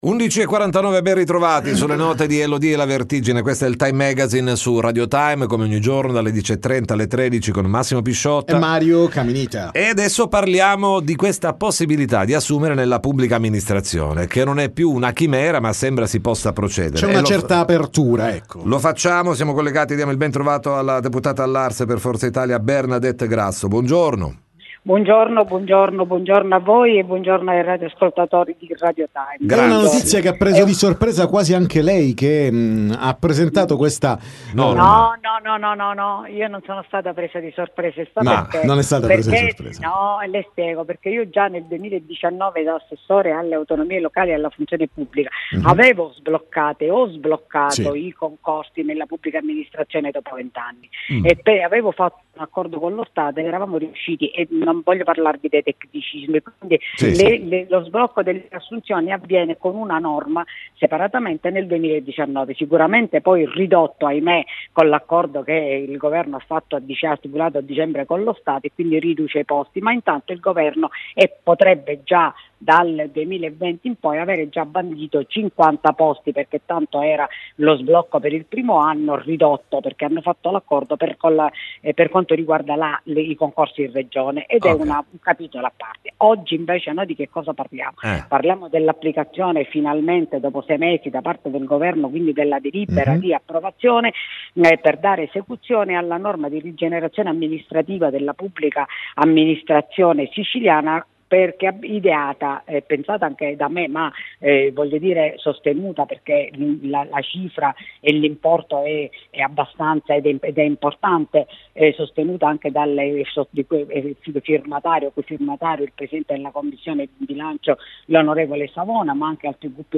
TM Intervista Bernadette Grasso